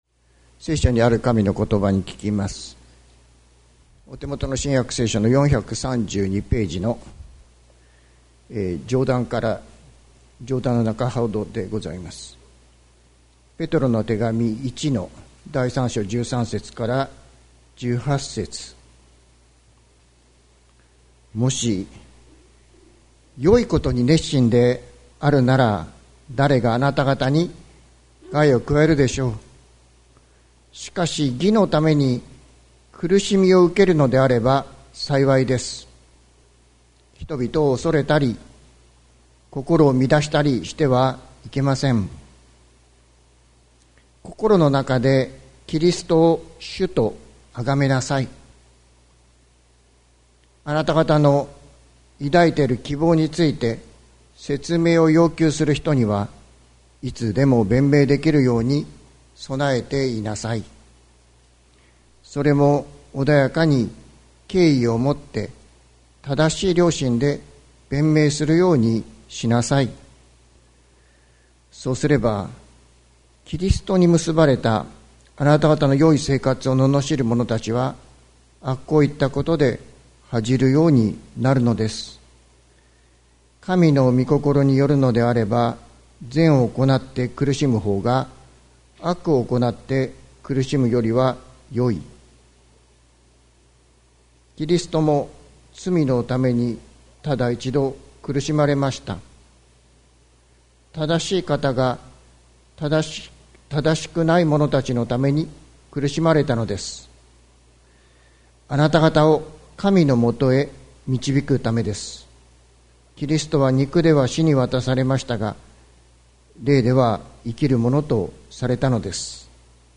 関キリスト教会。説教アーカイブ。